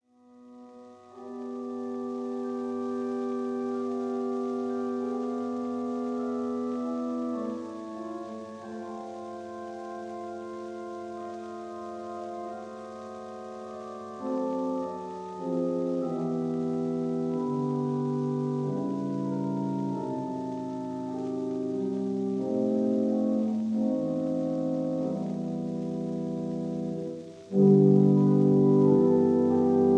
This is a 1948 recording made in St Marks Church, North a